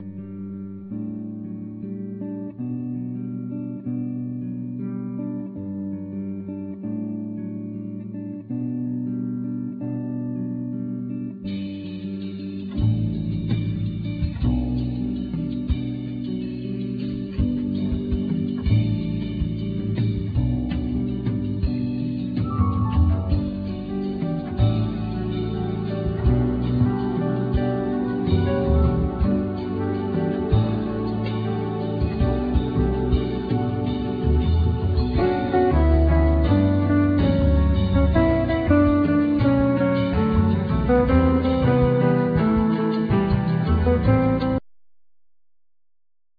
Guitar,Keyboards
Drums
Keyboards,Piano,Voice
Percussion
Double Bass
Vocal